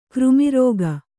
♪ křmi rōga